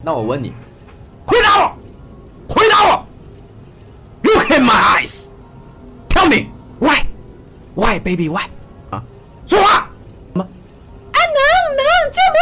用于校园导览和招生的专业大学介绍配音
使用专为高等教育营销、虚拟导览和新生入学指导设计的自然 AI 语音，提供权威而热情的校园介绍。
文本转语音
鼓舞人心的音调
它采用先进的神经合成技术开发，完美平衡了学术权威性与热情的温暖感。